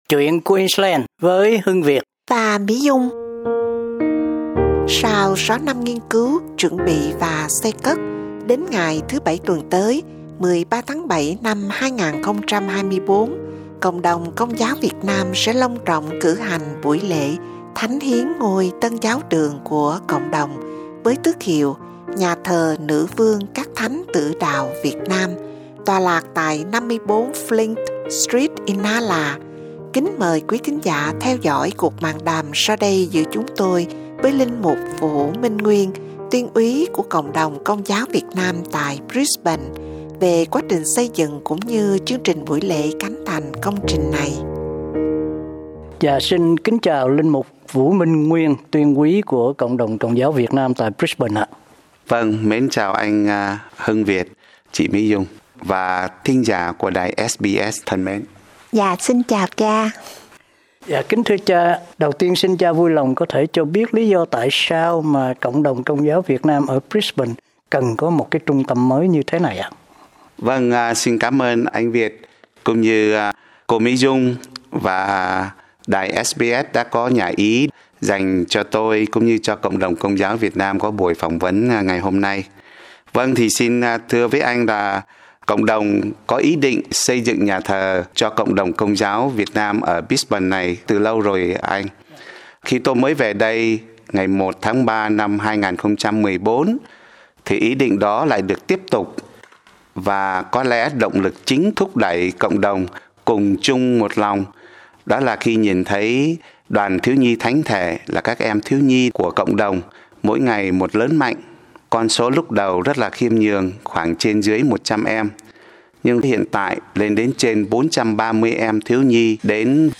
cuộc mạn đàm